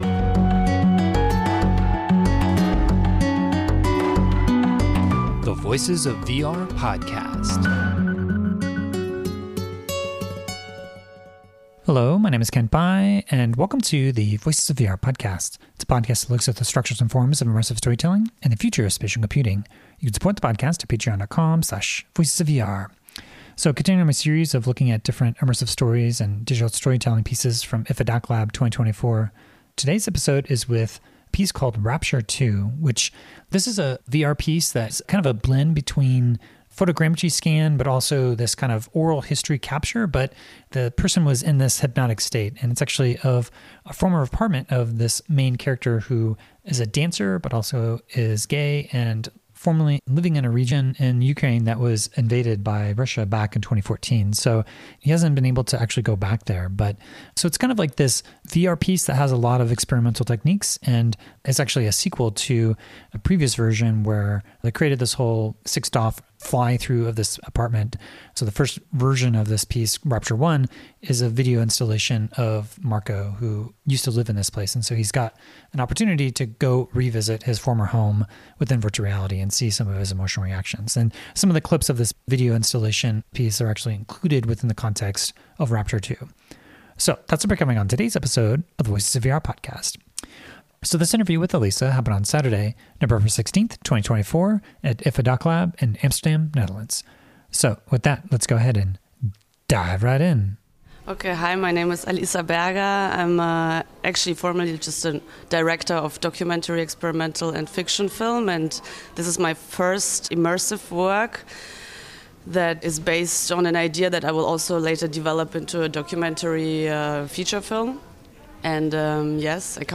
I interviewed director